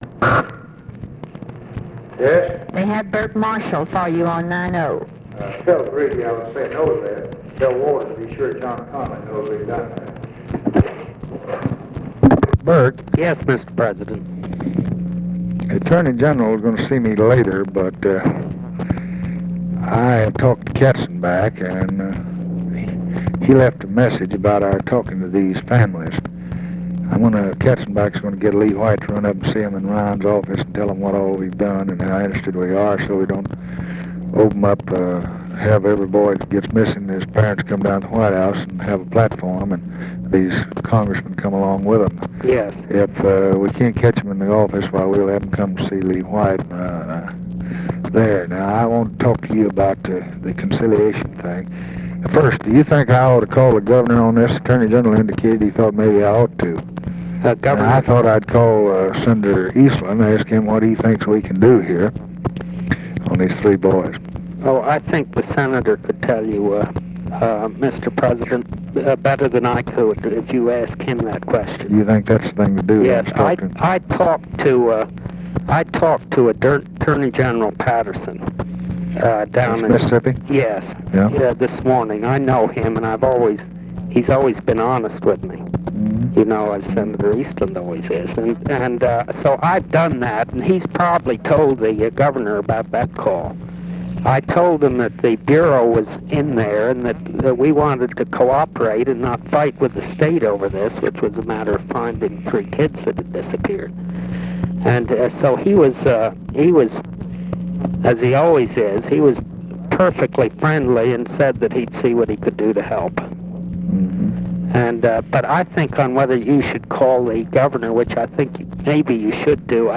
Conversation with BURKE MARSHALL, June 23, 1964
Secret White House Tapes